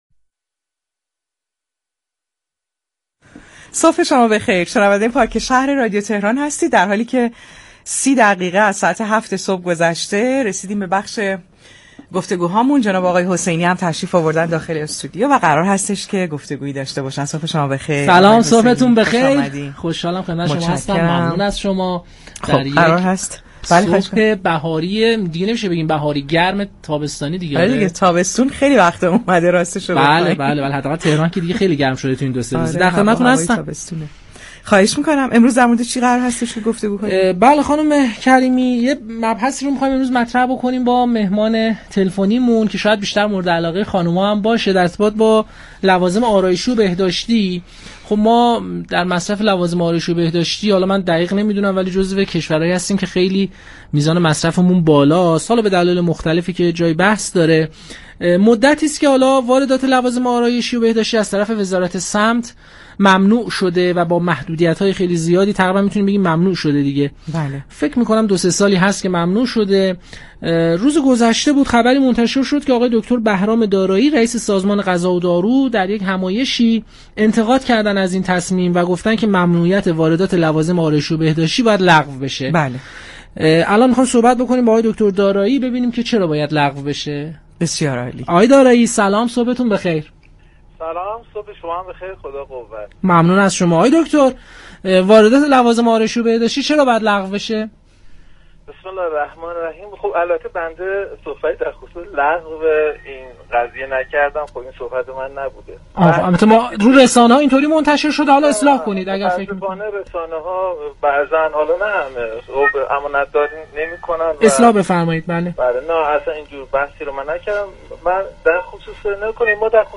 بهرام دارایی رئیس سازمان غذا و دارو در گفتگو با پارك شهر رادیو تهران